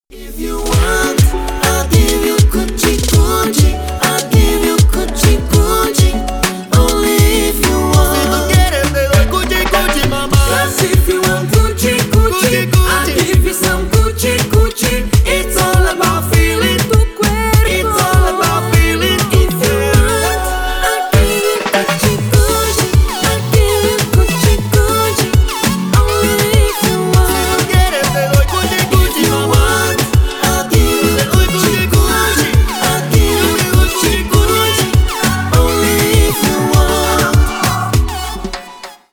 латинские , поп